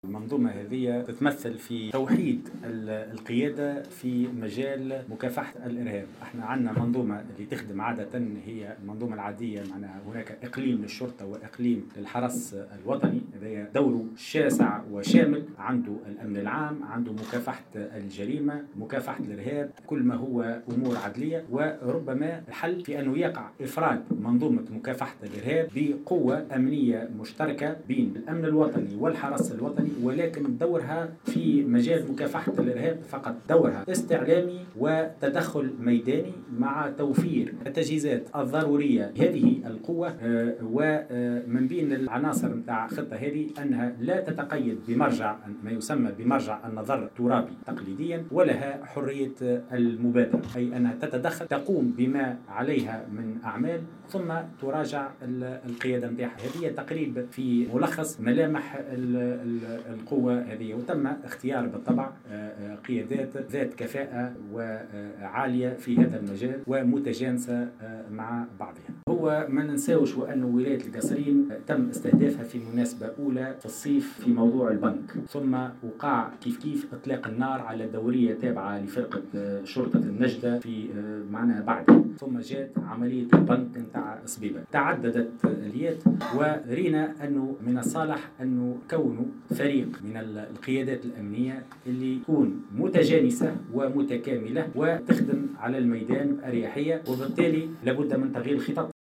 وأوضح في ندوة صحفية اثر جلسة استماع مغلقة له بلجنة الأمن والدفاع اليوم بمجلس نواب الشعب، أنه سيتم من خلال هذه المنظومة تخصيص قوة أمنية مشتركة بين الشرطة والحرس الوطنيين تعنى بمكافحة الإرهاب فقط، مضيفا أنه سيتم تعميم هذه الخطة الأمنية مستقبلا في بقية الولايات الحدودية، وخاصة منها ولايتي جندوبة والكاف.